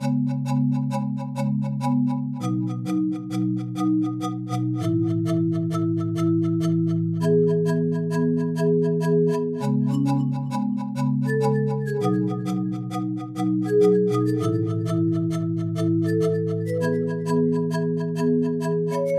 描述：Omnisphere（咖啡罐卡林巴硬币）和复古色彩
标签： 100 bpm Dancehall Loops Percussion Loops 3.23 MB wav Key : Unknown Ableton Live
声道立体声